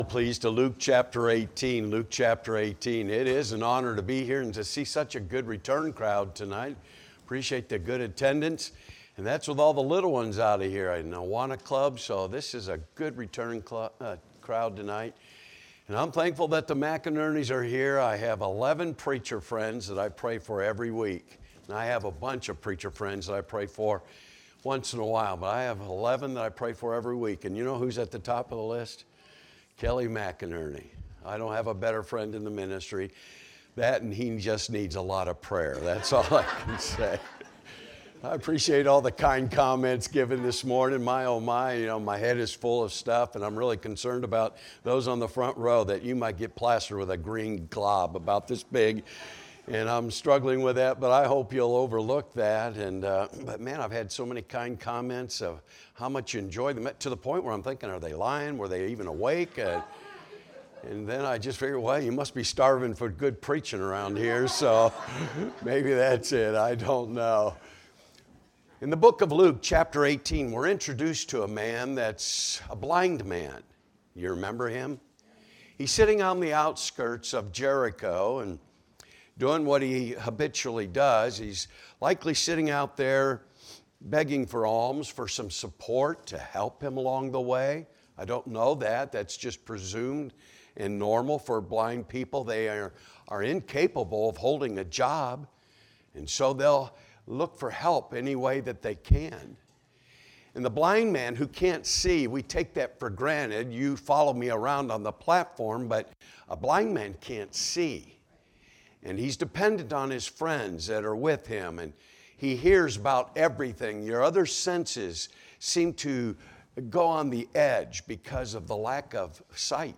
Sunday Evening Revival Service